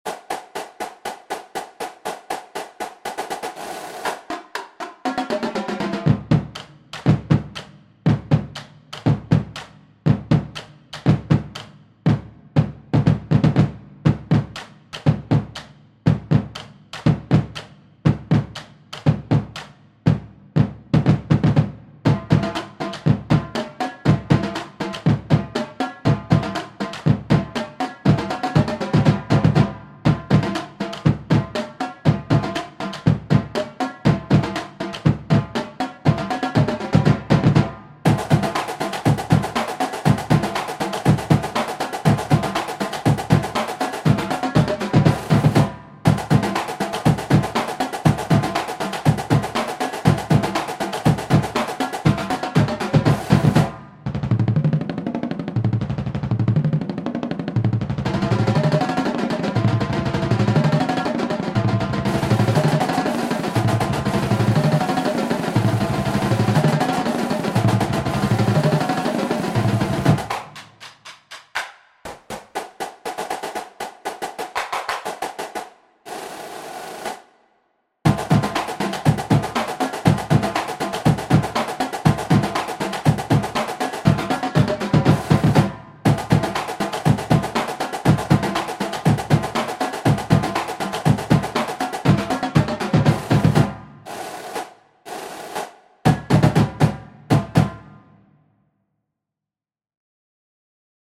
Another original drum cadence by me, and the first one I wrote that does not involve cymbals..
Labrador Retrievers (Original Drum Cadence)
drumline cadence for battery percussion